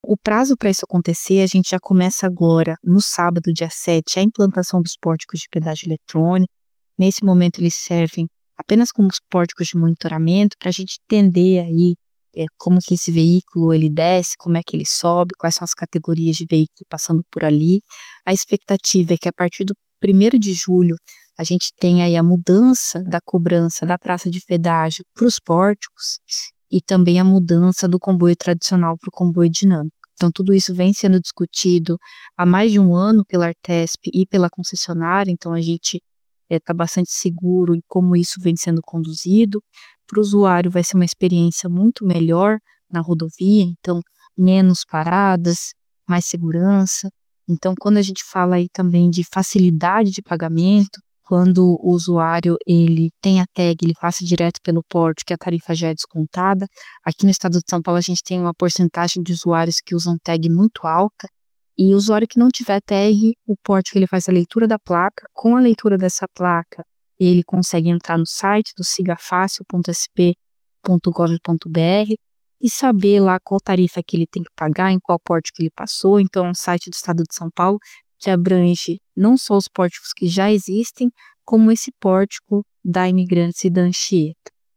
Raquel Carneiro , diretora da Artesp fala das mudanças:
Raquel-Carneiro-Diretora-da-ARTESP.mp3.mp3